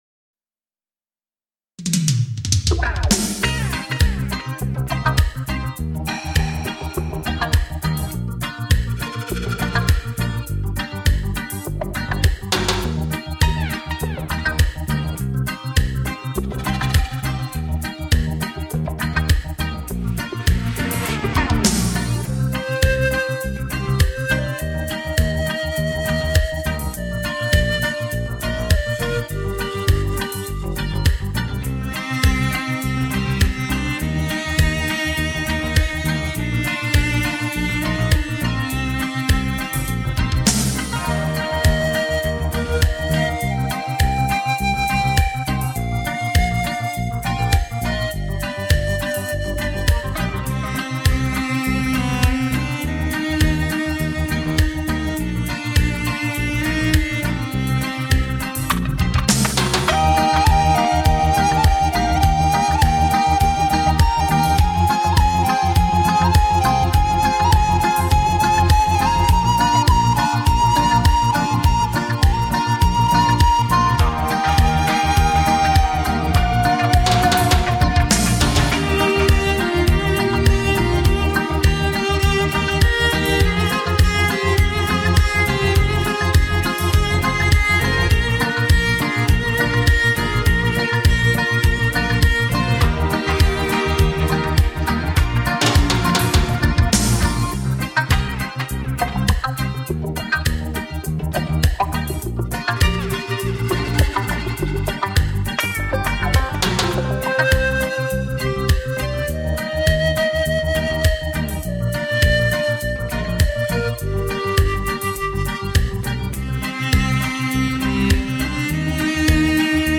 两种截然不同的乐器组合
一种独一无二的和谐音乐
自由、浪漫、热情、奔放......